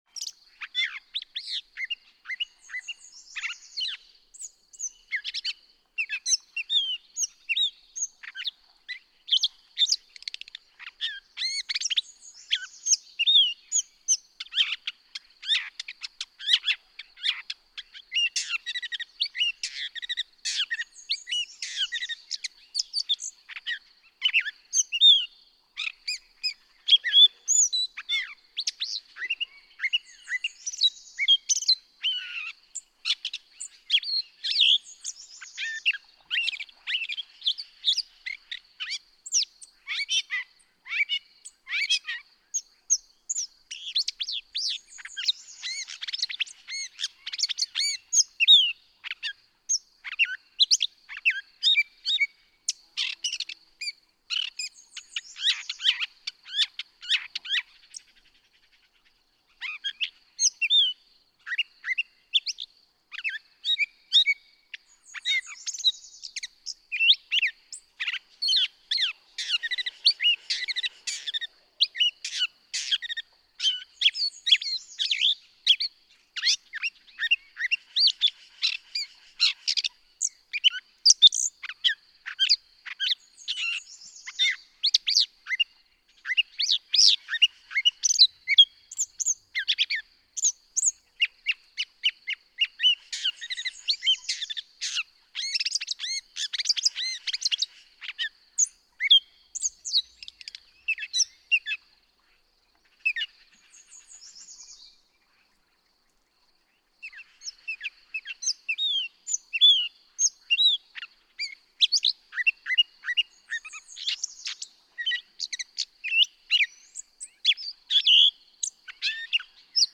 Gray catbird
♫161. Song, with many imitations, such as those of willow flycatcher fitz-bew (e.g., at 0:01, 0:28), northern flicker klee-yer call (0:04), Say's phoebe song phrase (0:06, 0:13, 0:25), and more.
Oxbow, Hells Canyon, Oregon.
161_Gray_Catbird.mp3